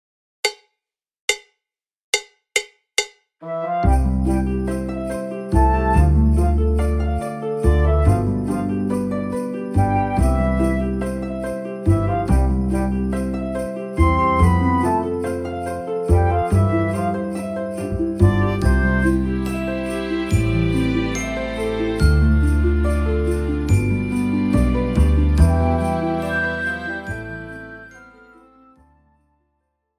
Besetzung: Schlagzeug